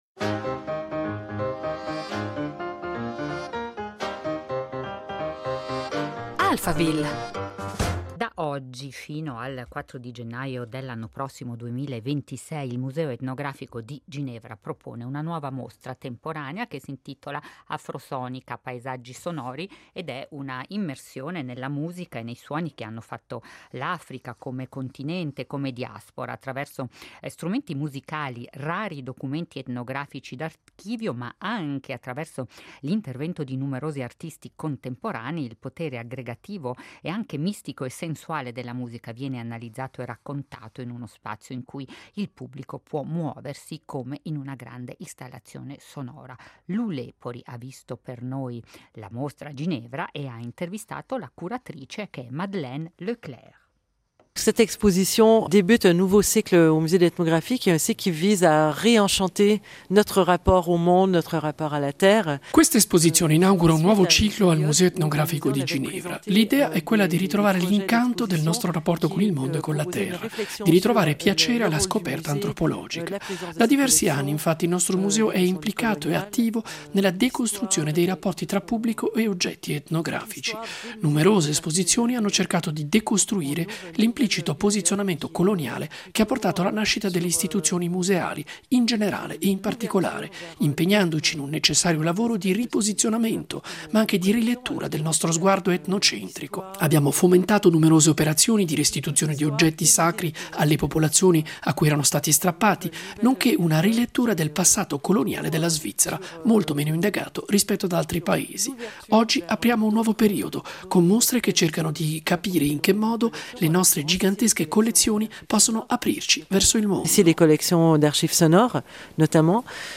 Alphaville